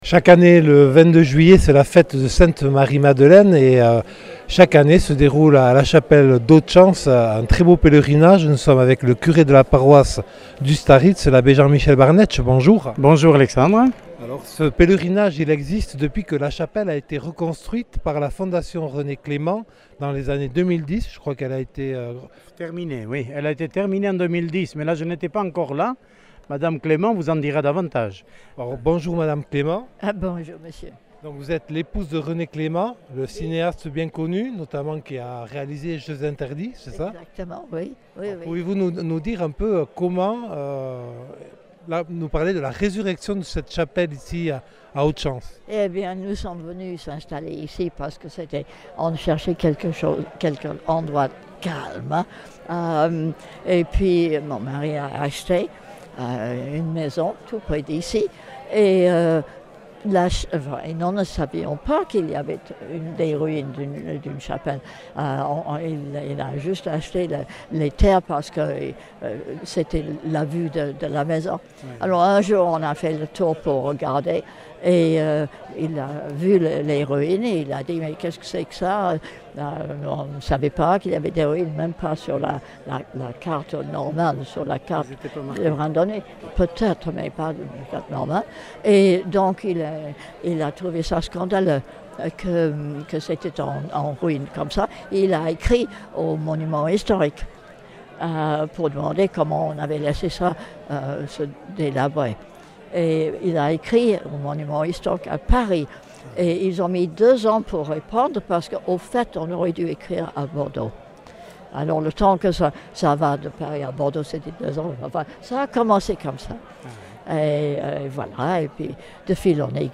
Accueil \ Emissions \ Infos \ Interviews et reportages \ Otsanz : la chapelle Sainte Marie-Madeleine comble pour célébrer l’Apôtre des (...)